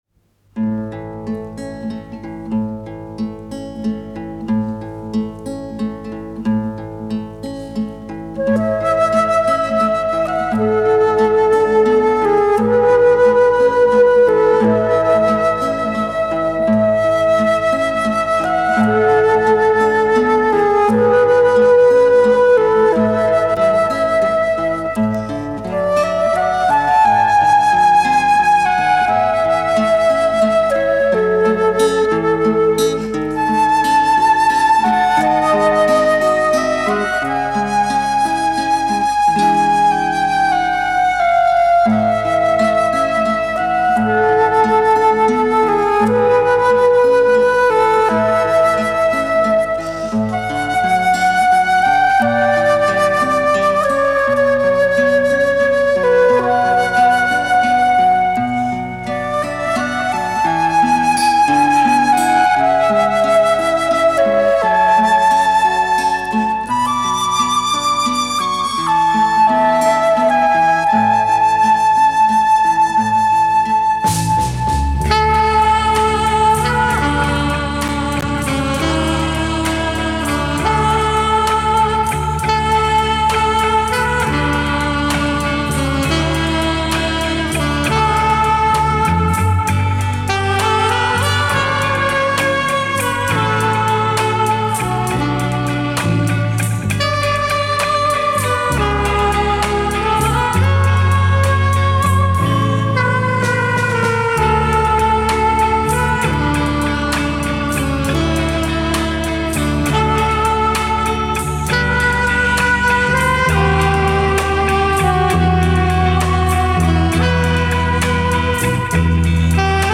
Песня без слов (вокализ, solo флейты и гитары) 2.
Сон девушки (solo трубы) 4.
Эскалатор (solo скрипок) 6.